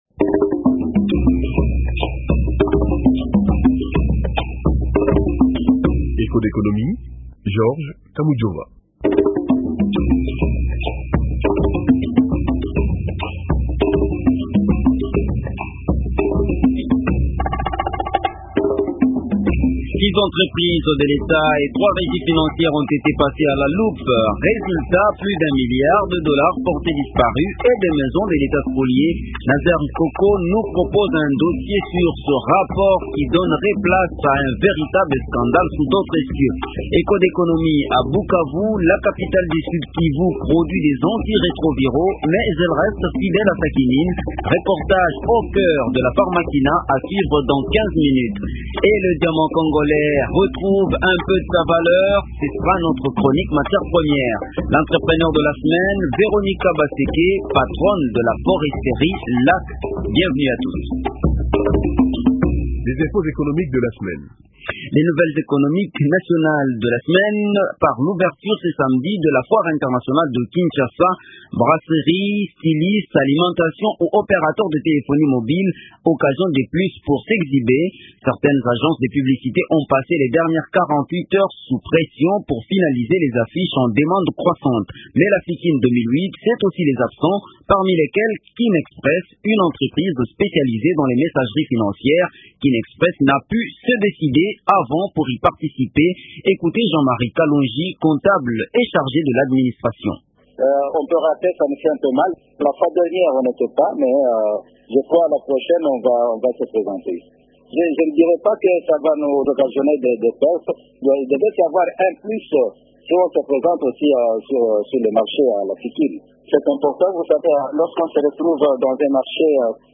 Reportage au cœur de la Pharmakina.